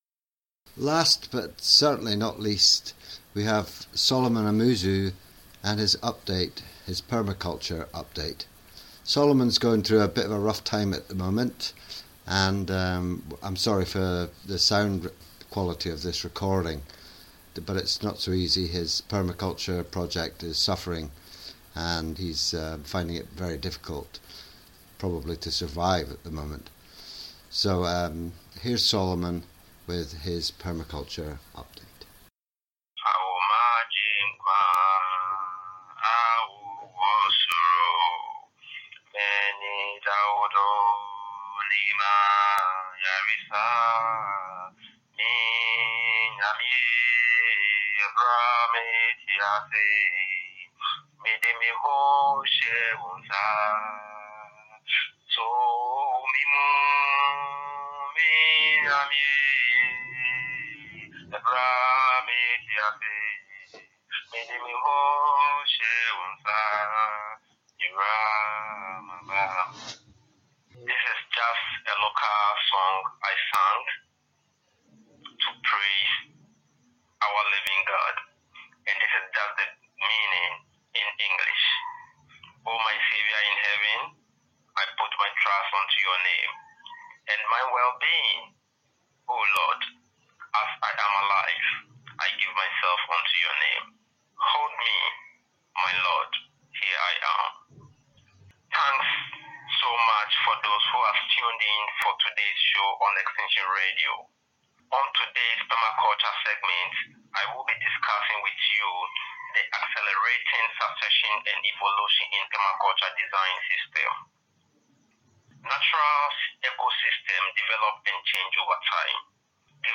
Originally aired on Extinction Radio 7 June 2015. Very poor sound quality.